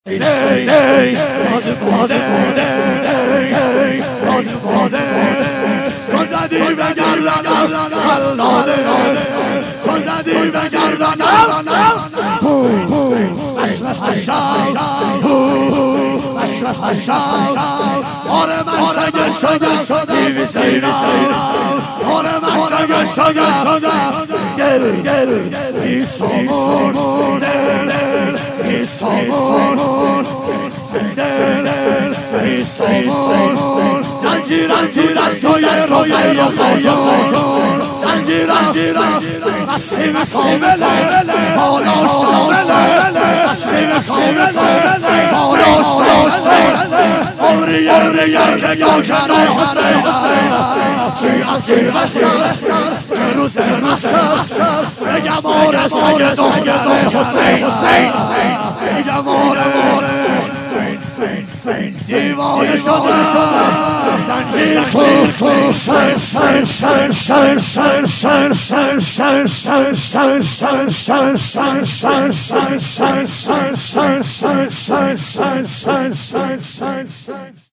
9- می باز باده - شور